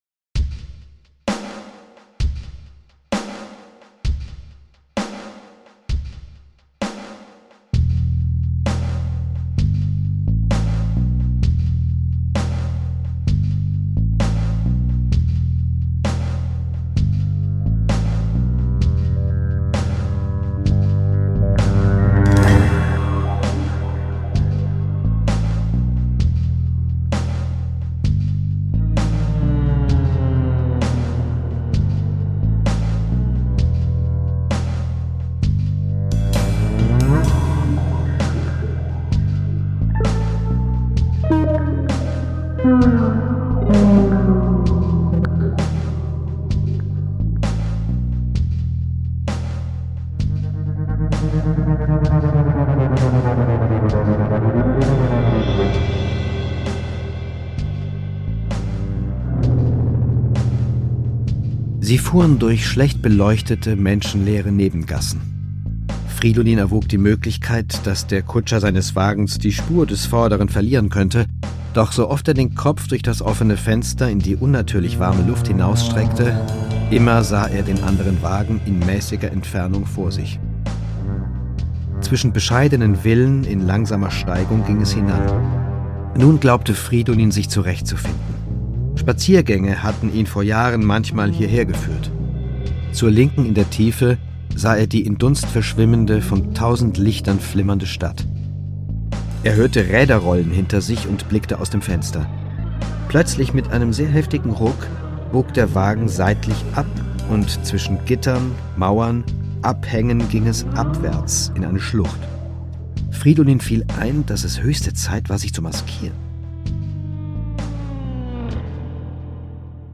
Konzertlesung nach Arthur Schnitzler
Produkttyp: Hörbuch-Download
Gelesen von: Andreas Pietschmann
Auf der Suche nach den verborgenen Sehnsüchten unserer Psyche beleben sie Schnitzlers bekannte Erzählung in einer atmosphärisch-dichten Klanglandschaft aus Sprache und Musik.